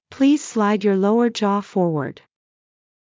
ﾌﾟﾘｰｽﾞ ｽﾗｲﾄﾞ ﾕｱ ﾛｳｱｰ ｼﾞｬｰ ﾌｫｰﾜｰﾄﾞ